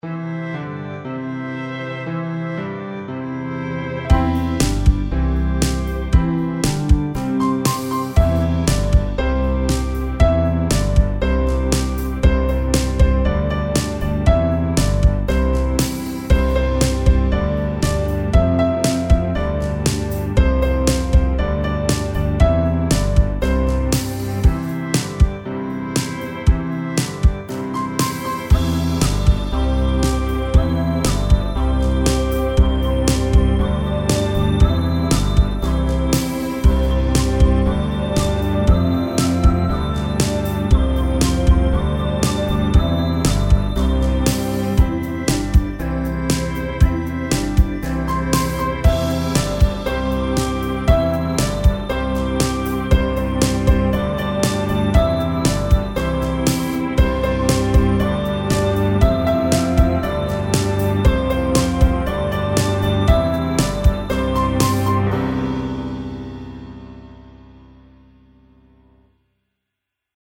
karaoke
Nursery rhyme, England (U.K.)
Kids Karaoke Song (Instrumental) YouTube License